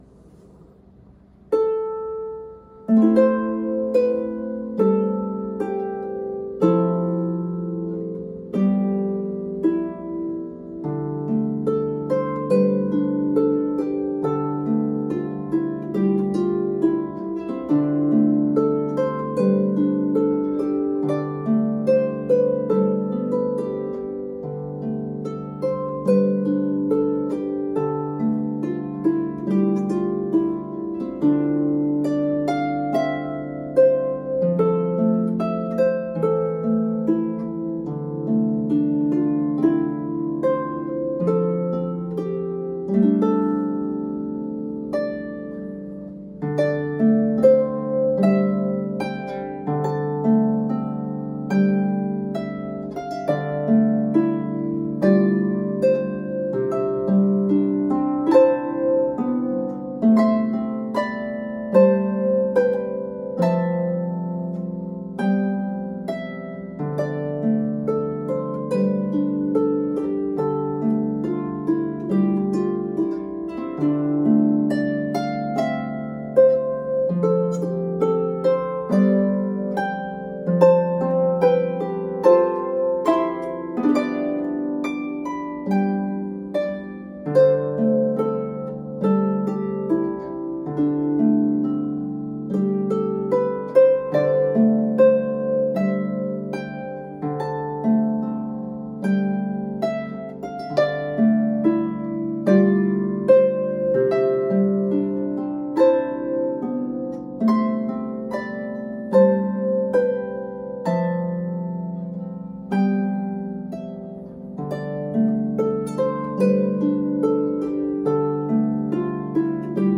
A peaceful, hypnotic meditation